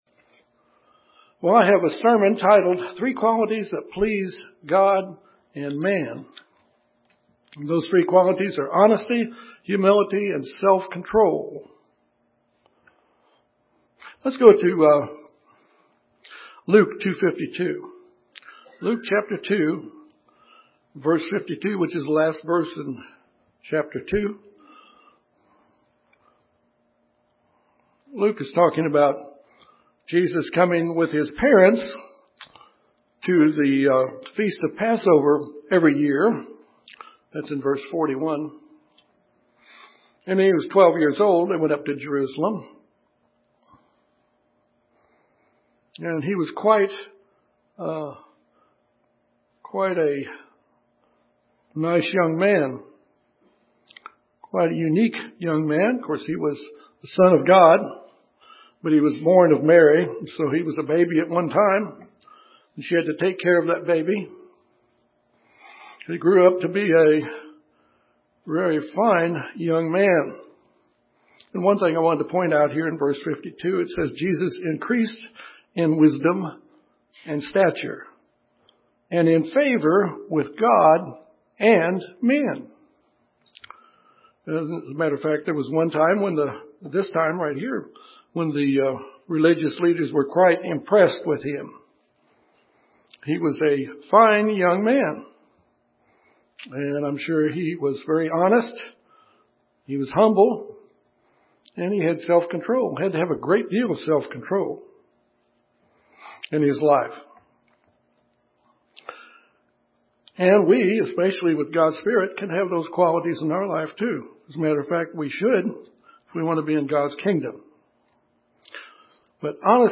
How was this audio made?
Given in Little Rock, AR Memphis, TN